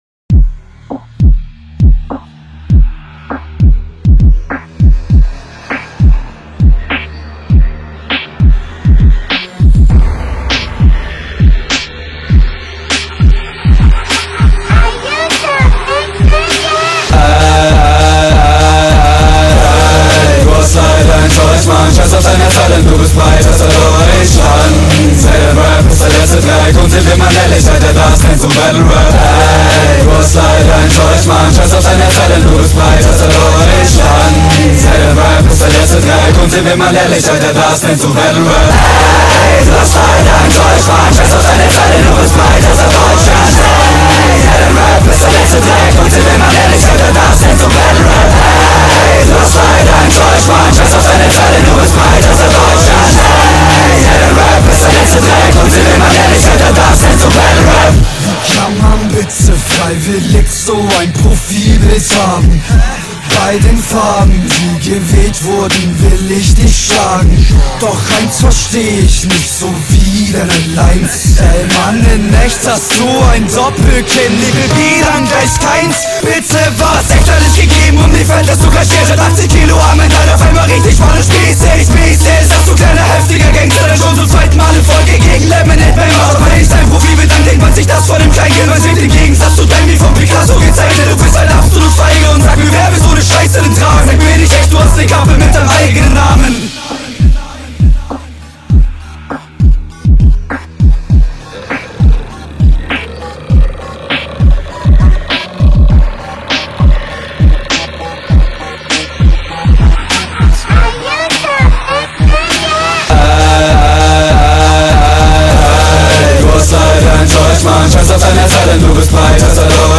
VIEL zu laute hook aber nice eh